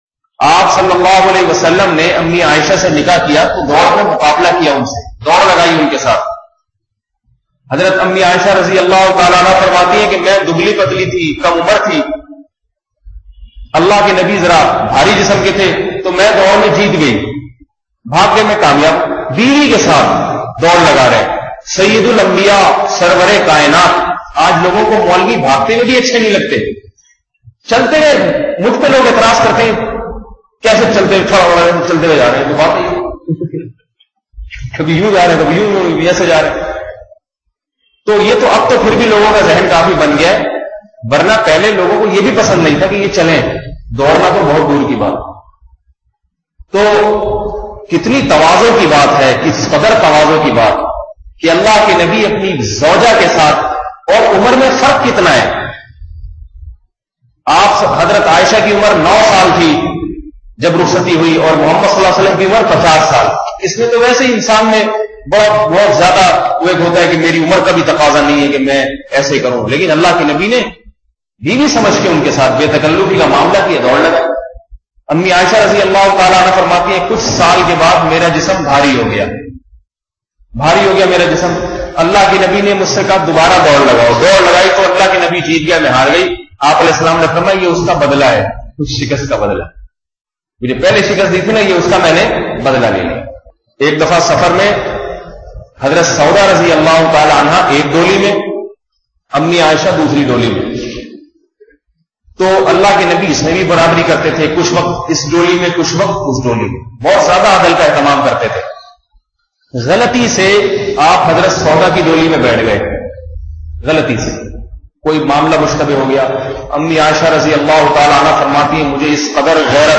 Audio Bayanat